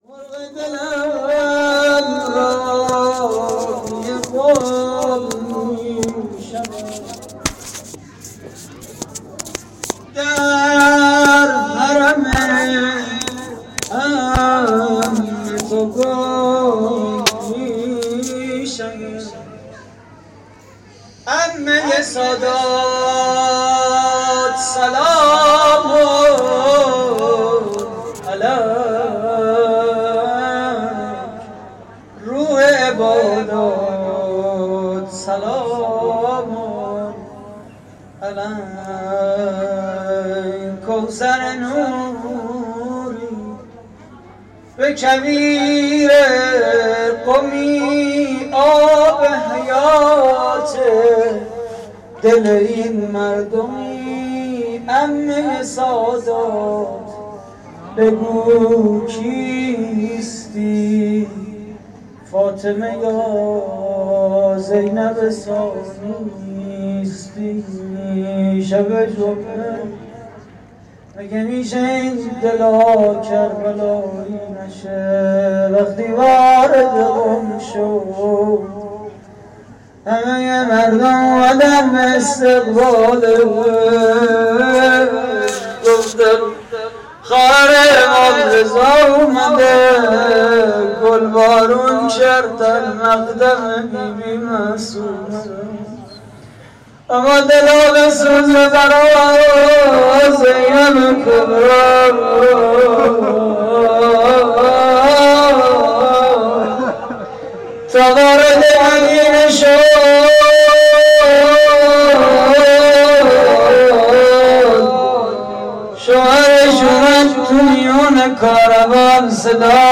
شعر پایانی: مرغ دلم راهی قم می‌شود
مراسم جشن ولادت حضرت معصومه (س)